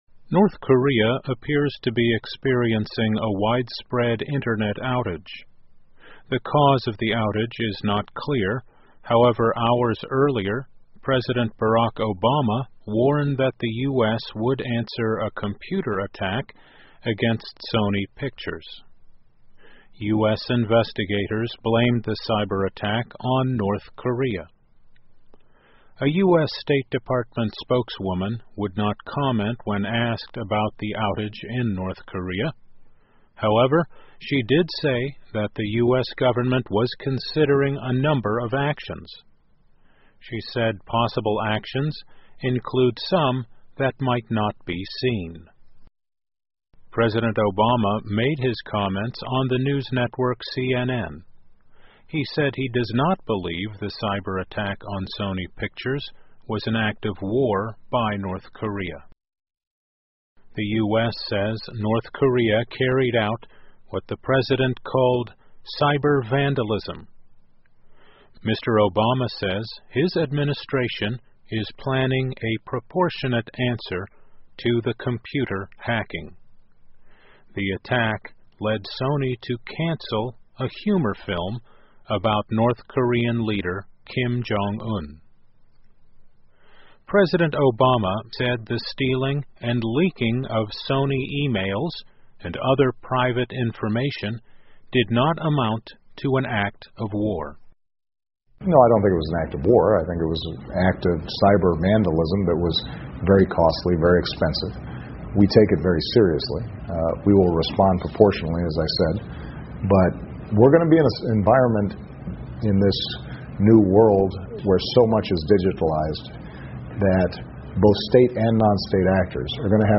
VOA慢速英语2014 报道称朝鲜网络一度中断 听力文件下载—在线英语听力室